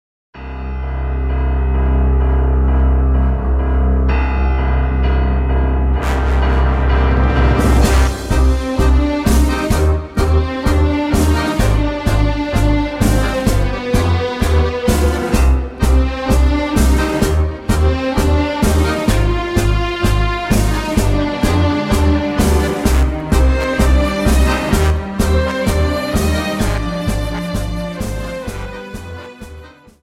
Dance: Tango 32 Song